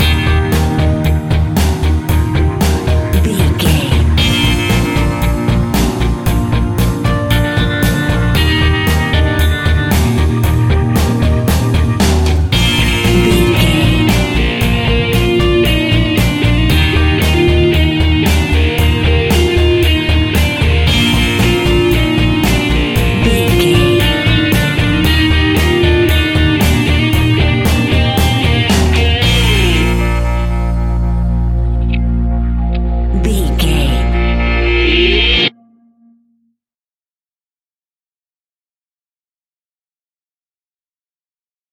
Uplifting
Ionian/Major
B♭
pop rock
indie pop
fun
energetic
cheesy
guitars
bass
drums
piano
organ